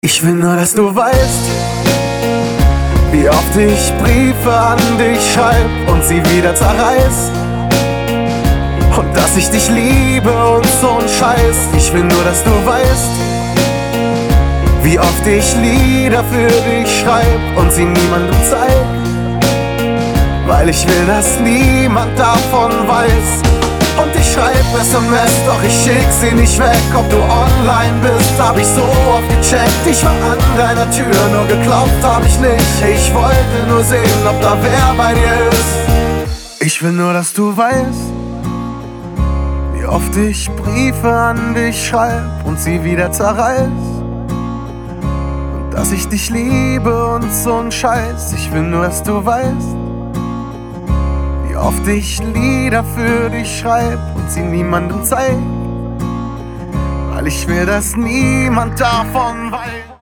• Качество: 320, Stereo
гитара
мужской вокал